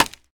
Minecraft Version Minecraft Version snapshot Latest Release | Latest Snapshot snapshot / assets / minecraft / sounds / block / scaffold / place1.ogg Compare With Compare With Latest Release | Latest Snapshot